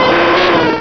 sound / direct_sound_samples / cries / arcanine.wav
arcanine.wav